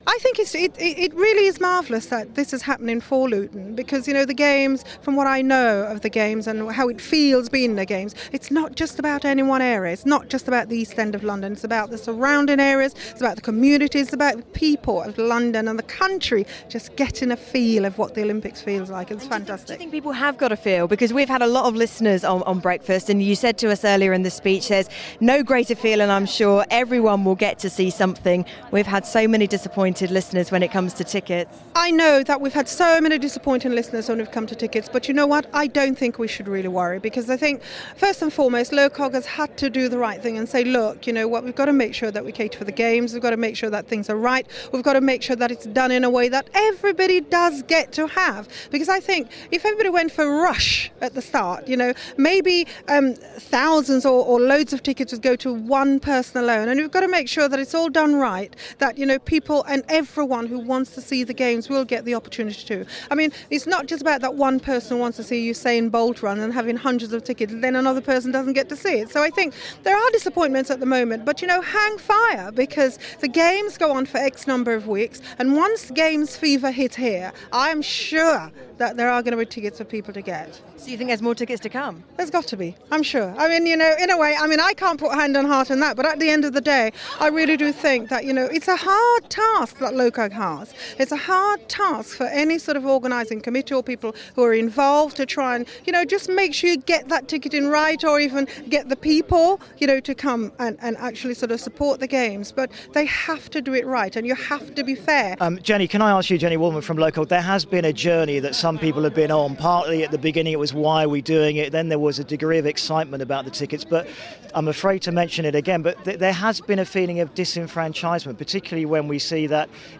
Tessa Sanderson, the Olympic and Commonwealth champion told them how Luton's connection to the Olympic games would benefit the town...